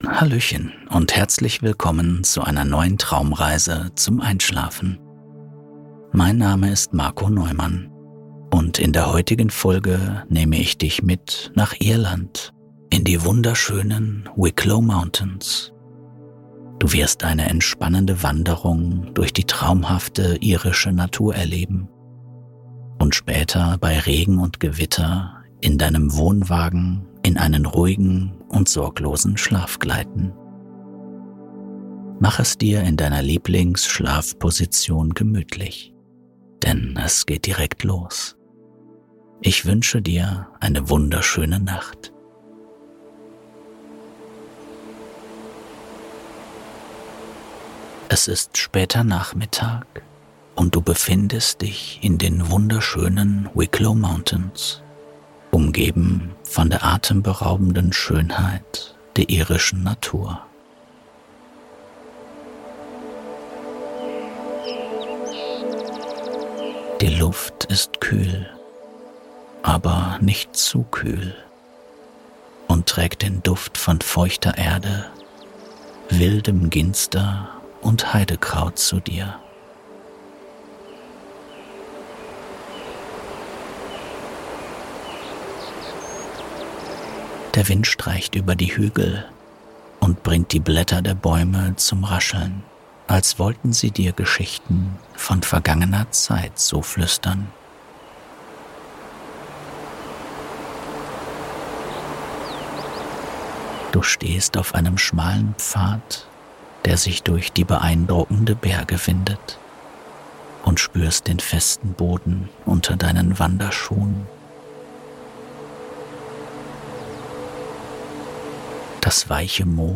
Irlands wilde Seite: Wicklow Mountains + Gewitter = PURE Entspannung! ⚡